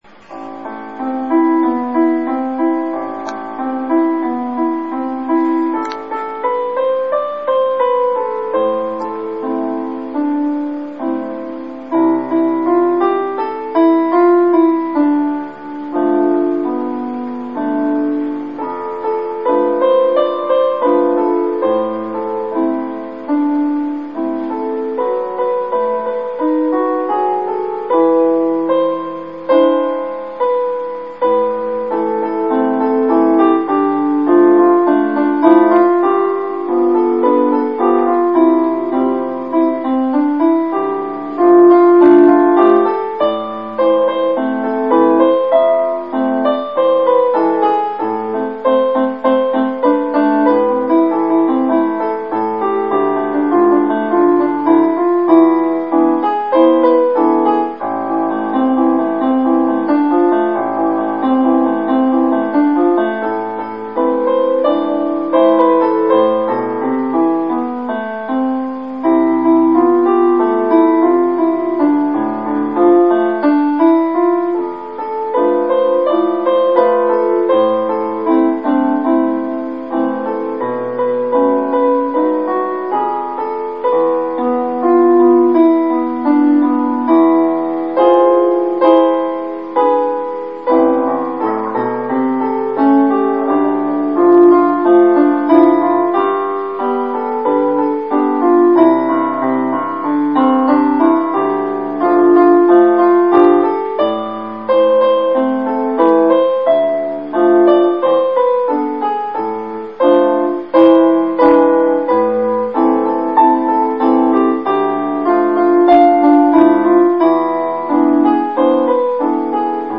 Partitura sencilla para piano/Easy piano score (pdf).